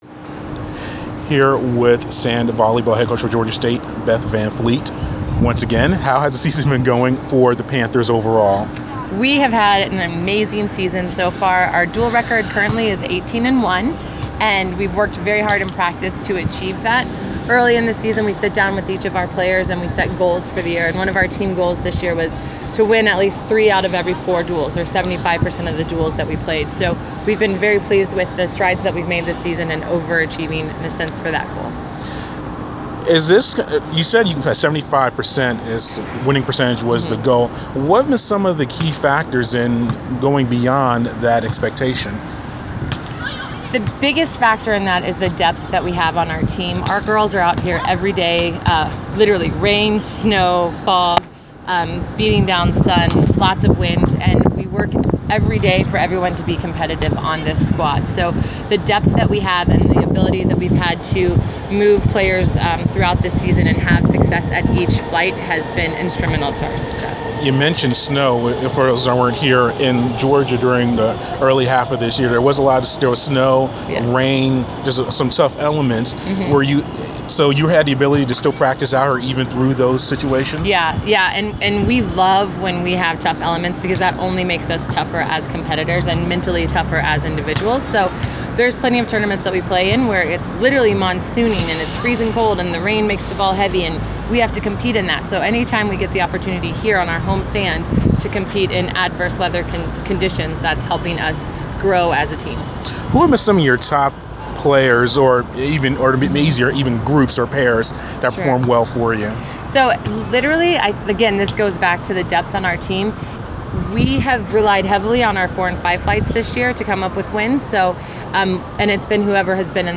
Inside the Inquirer: Interview